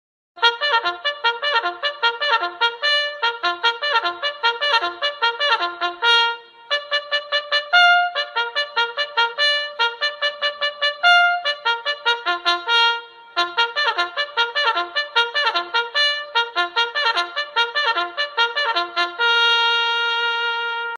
VIENTO METAL
Sonido+De+Trompeta (audio/mpeg)
TROMPETA